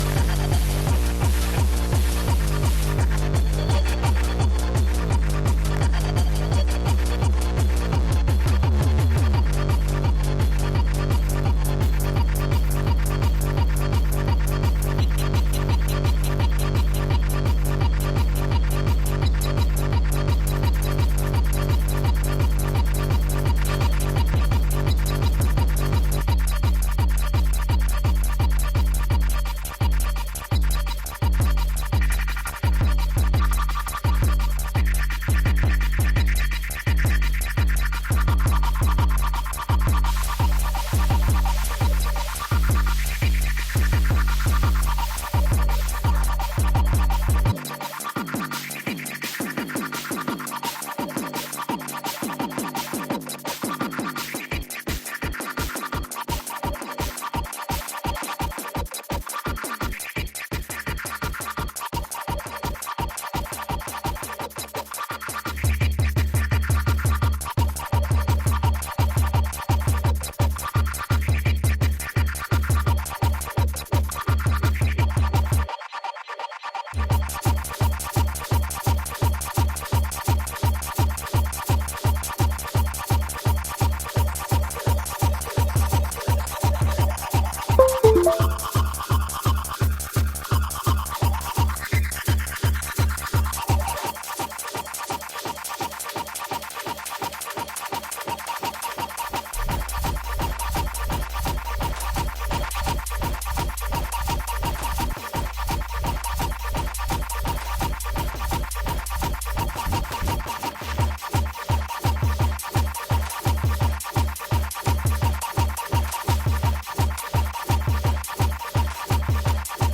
Zu zerstückelt, um ein Set sein zu können.